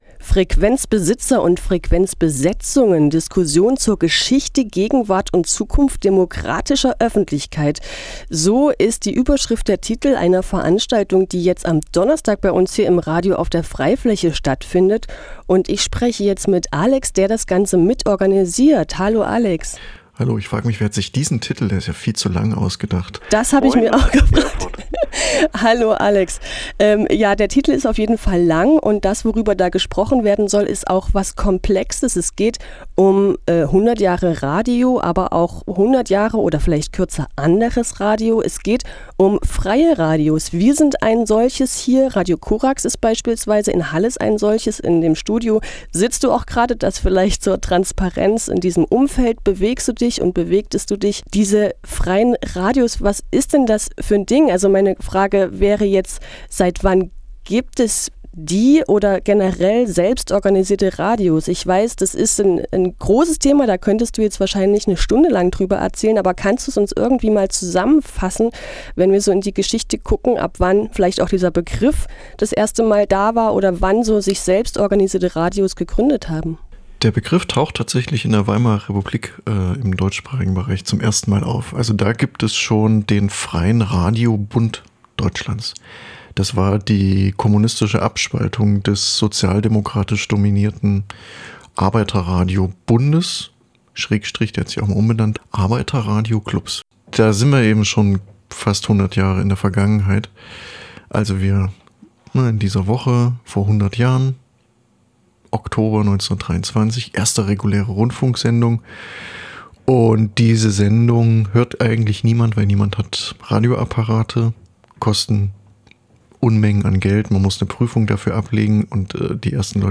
[Neu: Interview] 26. Oktober 2023 | 19.00 Uhr | Frequenzbesitzer und Frequenzbesetzungen | F.R.E.I.-Fläche